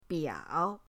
biao3.mp3